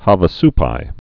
(hävə-spī)